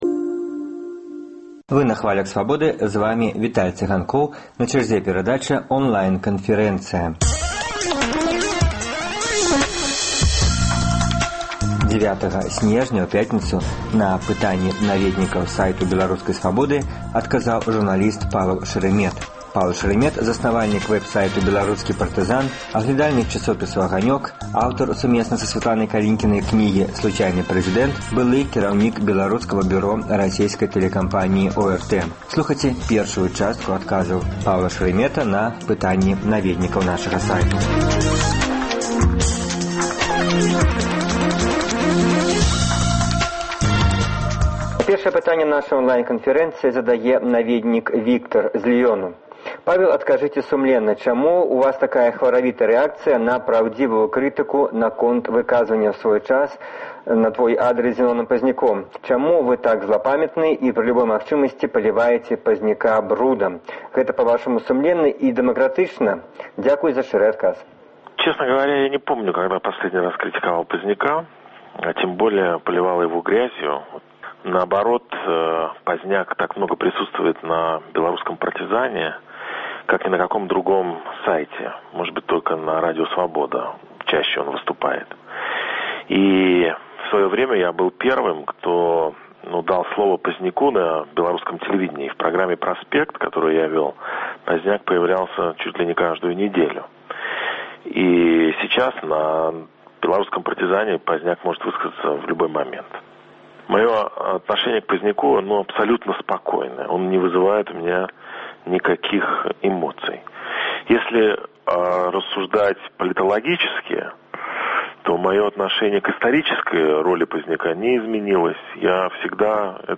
Онлайн-канфэрэнцыя
Онлайн-канфэрэнцыя з журналістам Паўлам Шараметам, заснавальнікам вэбсайту «Беларускі партызан», аглядальнікам часопісу «Огонёк», аўтарам (сумесна са Сьвятланай Калінкінай) кнігі «Случайный президент», былым кіраўніком Беларускага бюро расейскай тэлекампаніі ОРТ.